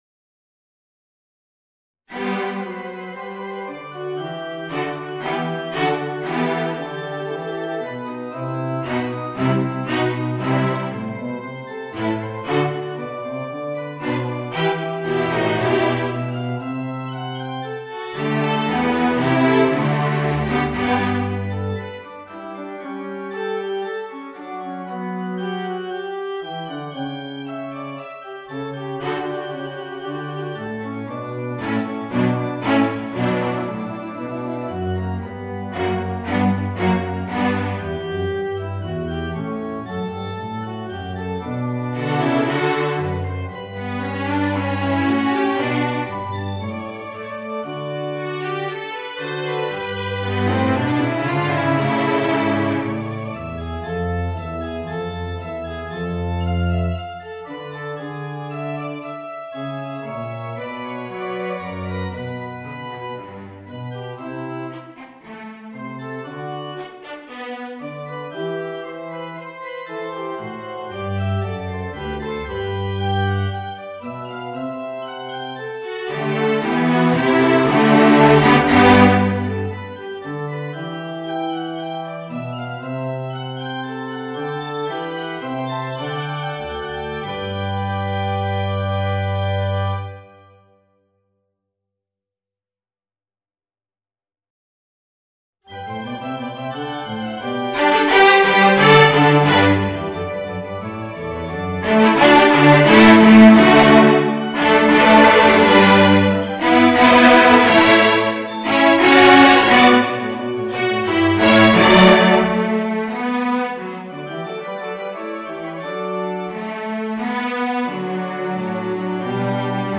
Voicing: Viola, 2 Violoncellos and Organ